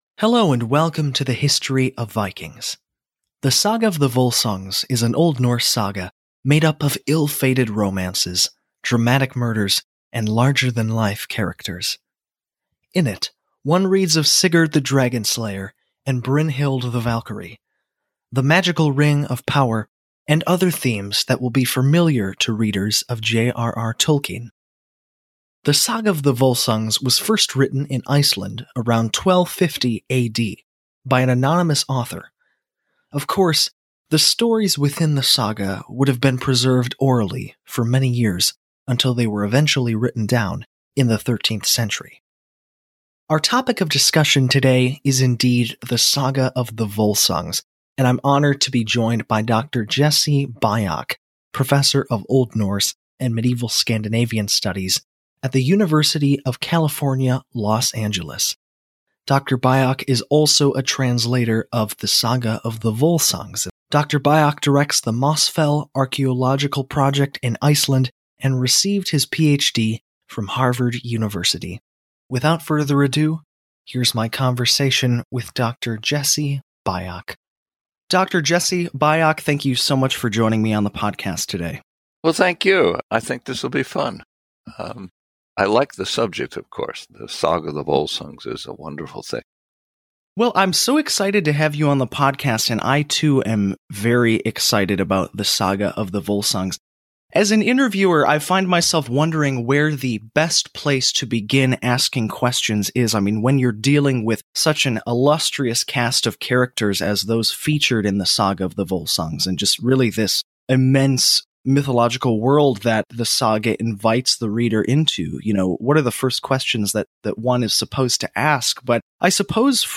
We’ve collected some of the best parts of their conversation here, but you can listen to the full podcast here.